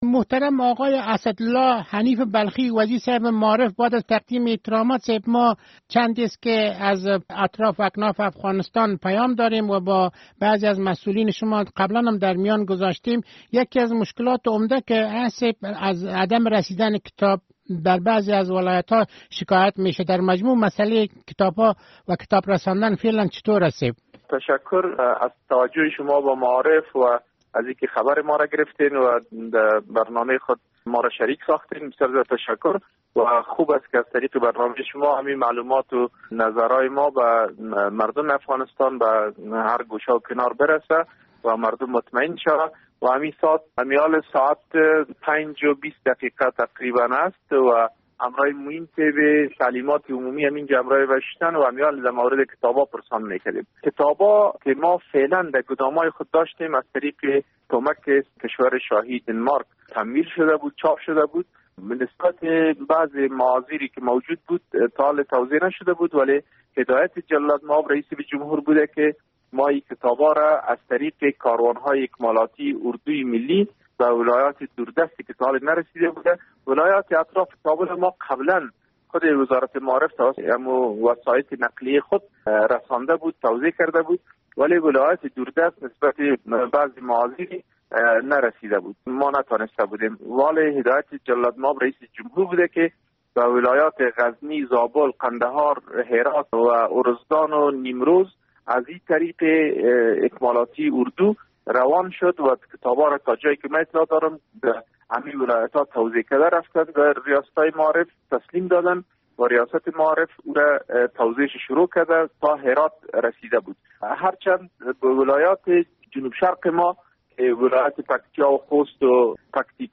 مصاحبه با وزیر معارف افغانستان